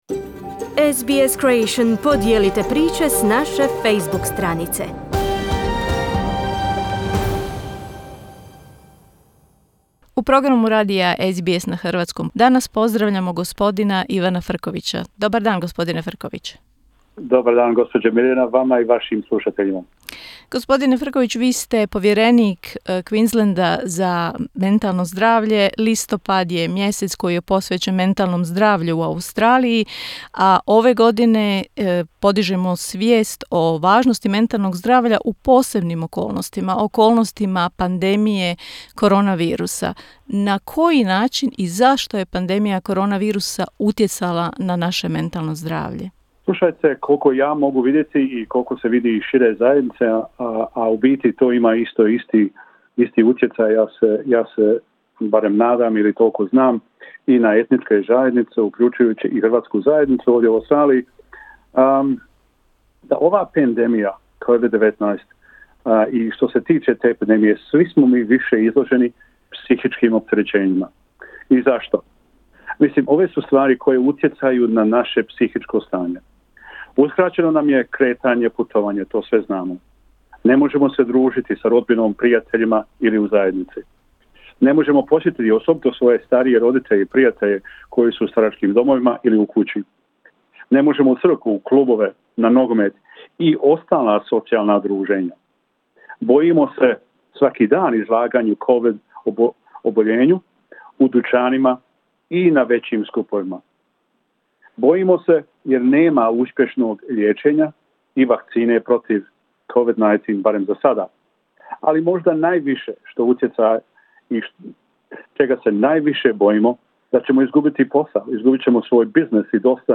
Ivan Frković, koji je upravo izabran na drugi trogodišnji mandat Povjerenika za mentalno zdravlje u Queenslandu, odgovara na pitanja o mentalnom zdravlju tijekom pandemije koronavirusa.